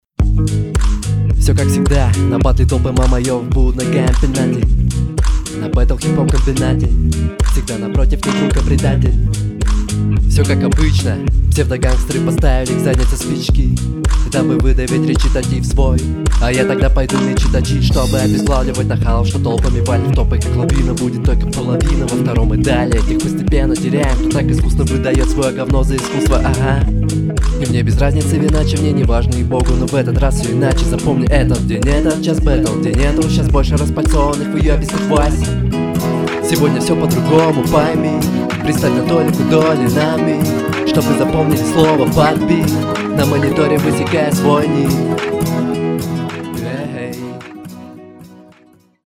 Ты тараторишь, но себе под нос, как я слышу. Можно было сделать громче, а минус чуть убавить, тогда было бы замечательно.
Слова жуешь.
У музыки больше настроения, чем у тебя, подача тихони, очень скромно произносишь